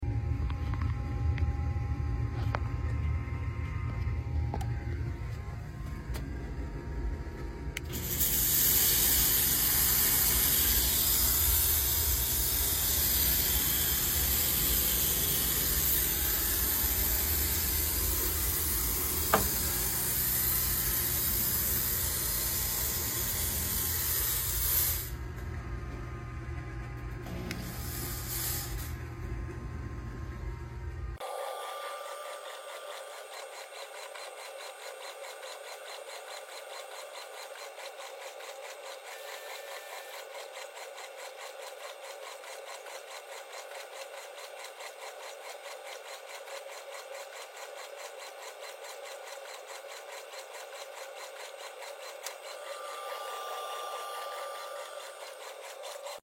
It was perfect timing because both the washing machine and water heater were running. With the high speed it created this cool noise track!